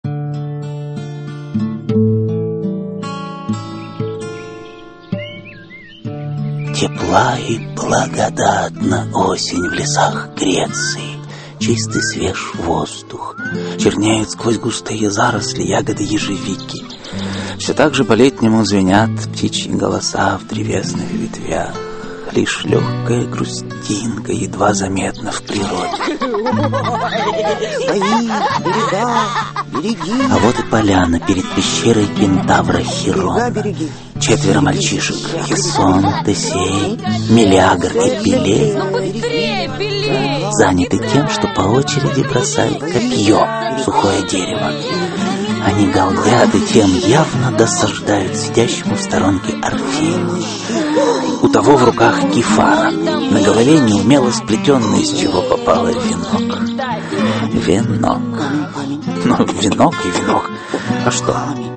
Аудиокнига Легенды и мифы Древней Греции. Персей. Аудиоспектакль | Библиотека аудиокниг